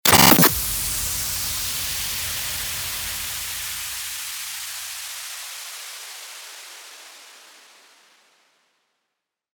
FX-1903-STARTER-WHOOSH
FX-1903-STARTER-WHOOSH.mp3